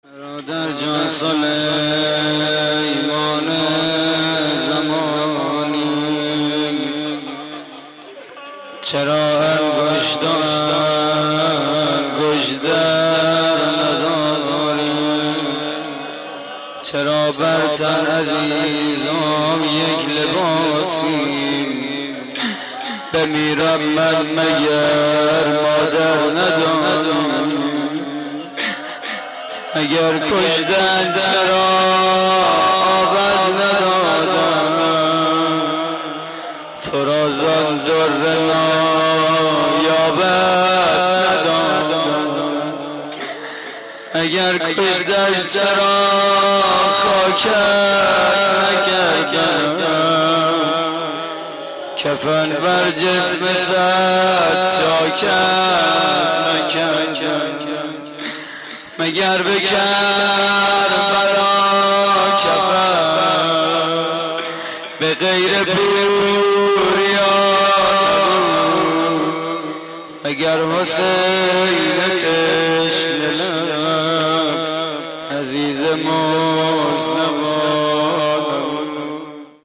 مراسم عزاداری شب عاشورای حسینی / هیئت محبان العباس (ع)